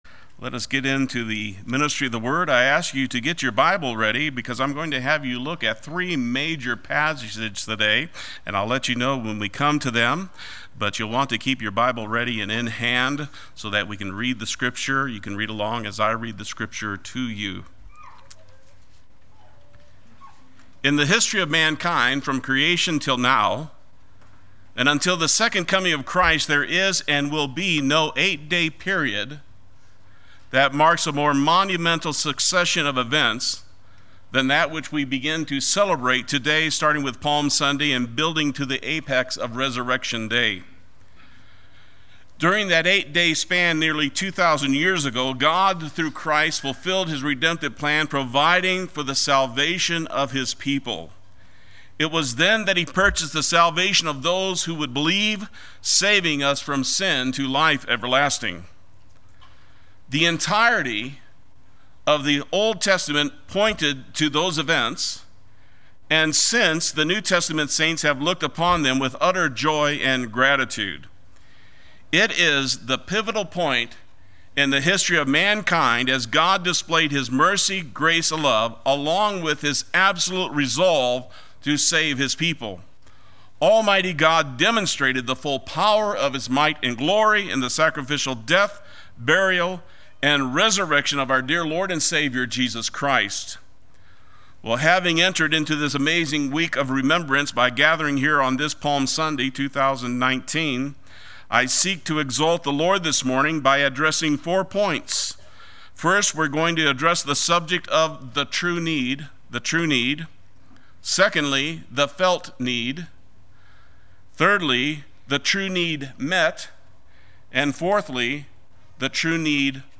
Play Sermon Get HCF Teaching Automatically.
The Just for the Unjust Sunday Worship